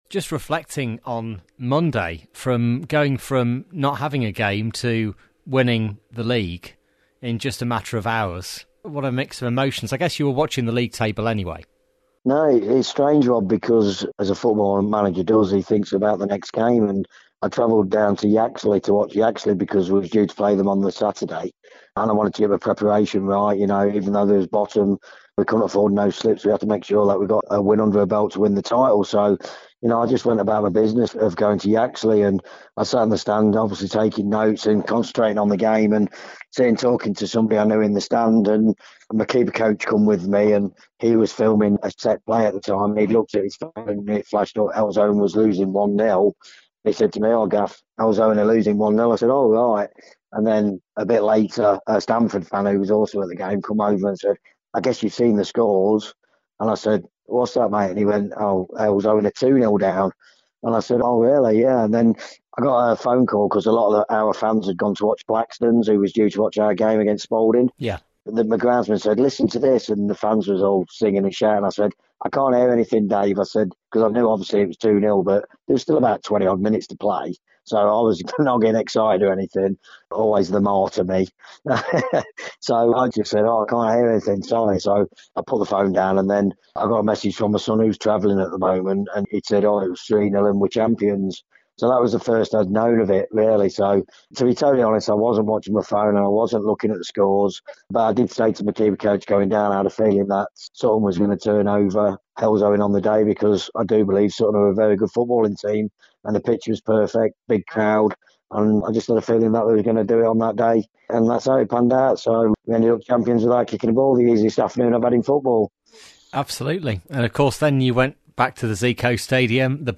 The Evening Mix